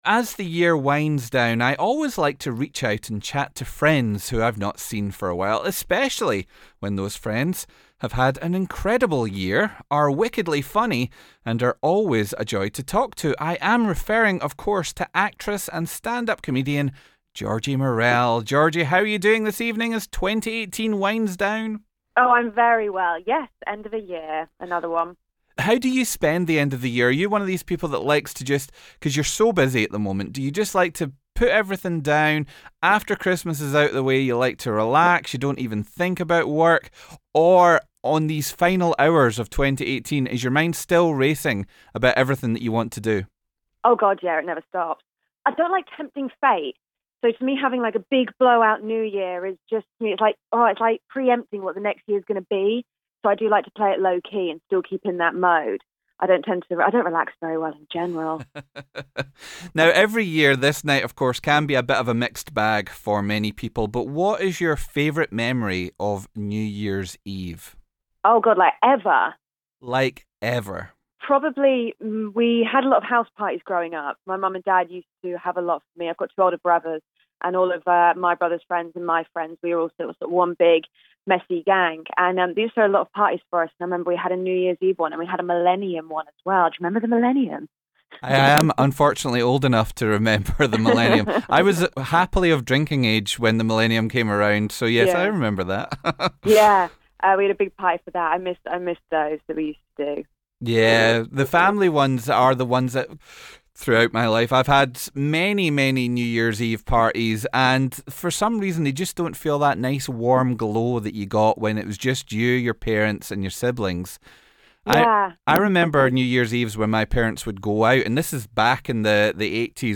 actress and stand-up comedian